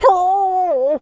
dog_hurt_whimper_howl_06.wav